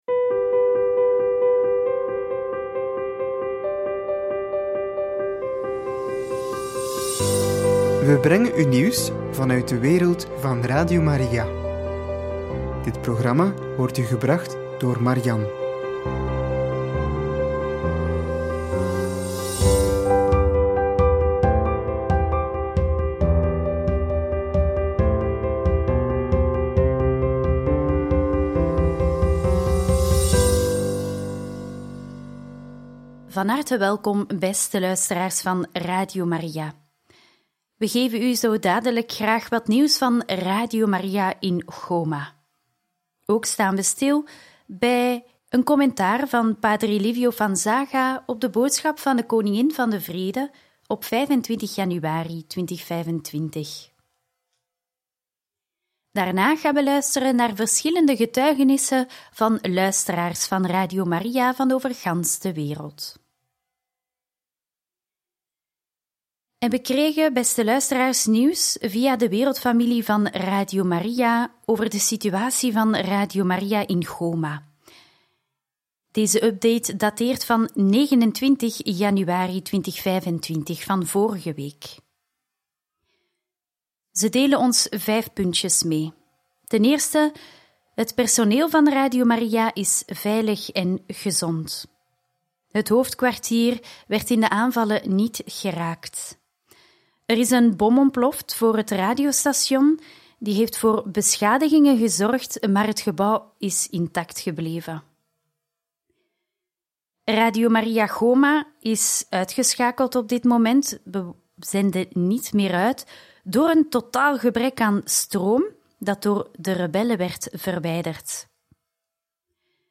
Getuigenissen van luisteraars van Radio Maria uit Jordanië, Chili en Ecuador – Radio Maria
getuigenissen-van-luisteraars-van-radio-maria-uit-jordanie-chili-en-ecuador.mp3